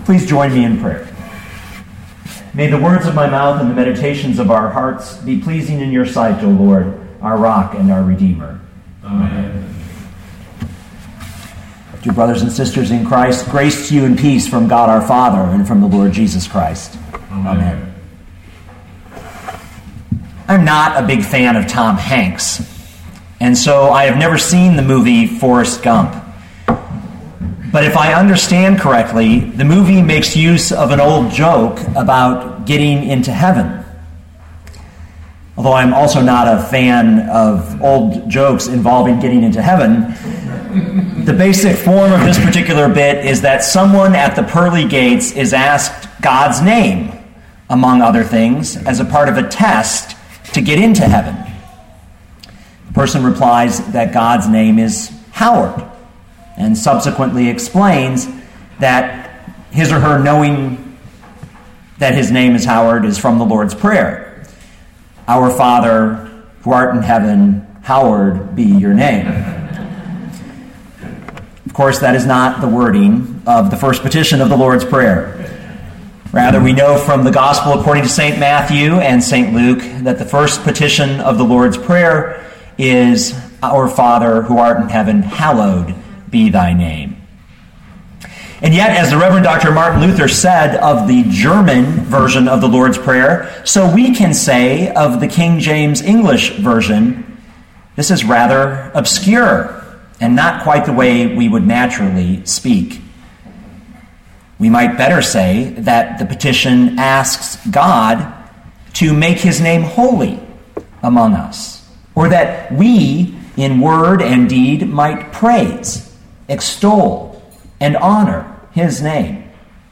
Luke 11:2 Listen to the sermon with the player below, or, download the audio.